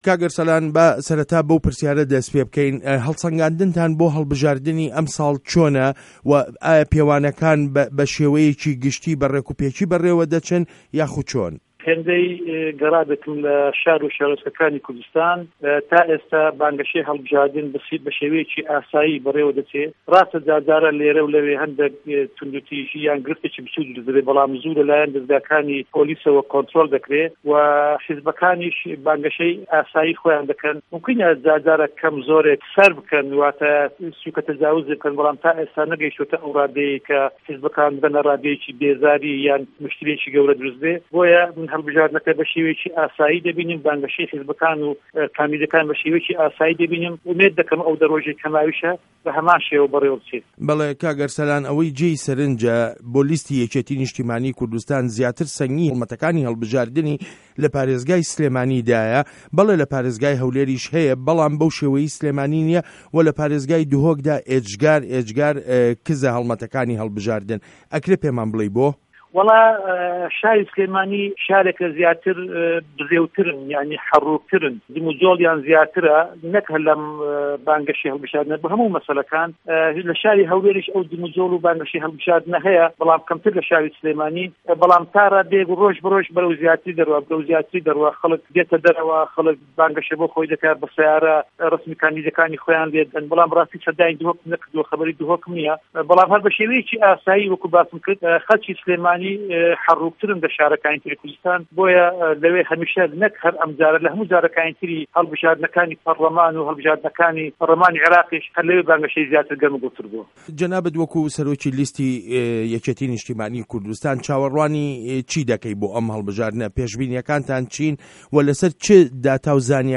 وتووێژ له‌گه‌ڵ ئه‌رسه‌لان بایز